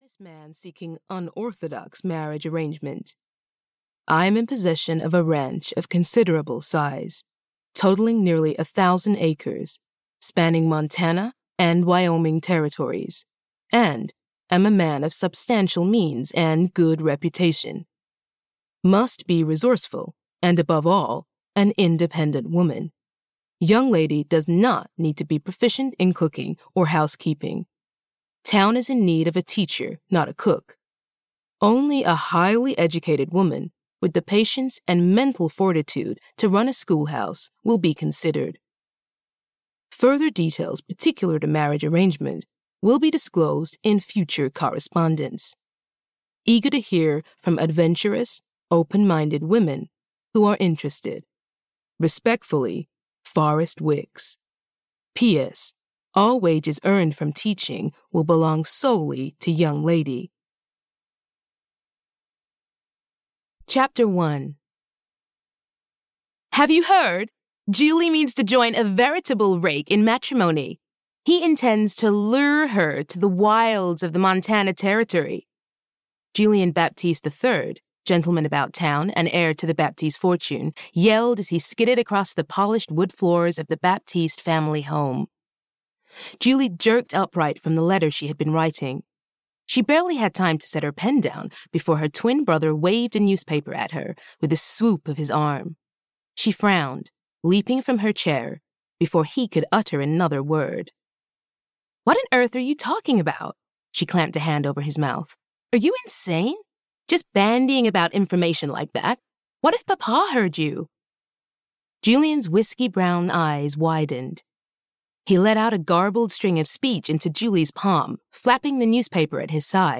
Heart and Hand (EN) audiokniha
Ukázka z knihy